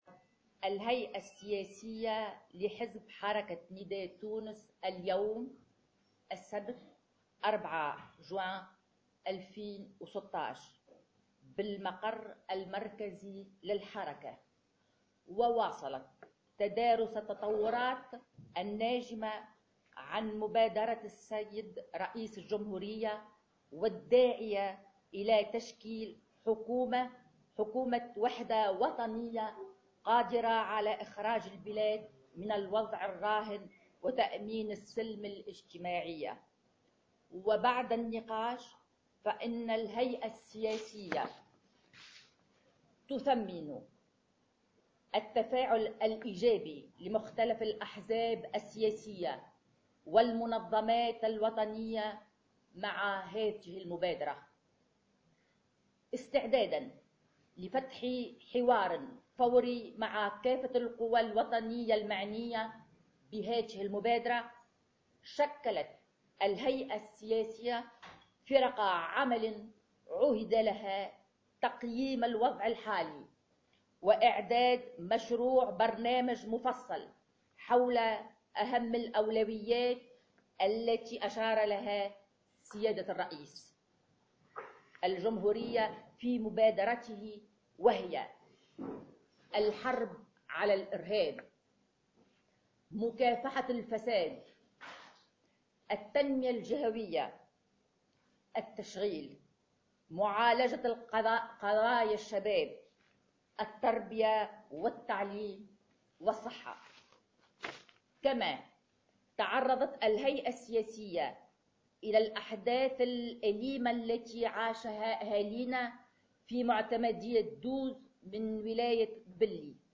وجاء في بيان تلته النائبة عن كتلة الحزب بالبرلمان، زهرة ادريس، على اثر اجتماع للهيئة السياسية للحزب مساء اليوم، أنه تم تشكيل فرق عمل لتقييم الوضع الحالي و اعداد مشروع برنامج مفصل حول أهم الأولويات التي أشار إليها رئيس الجمهورية في مبادرته و هي : الحرب على الإرهاب، مكافحة الفساد، التنمية الجهوية ، التشغيل ، معالجة قضايا الشباب ، التربية و التعليم و الصحة.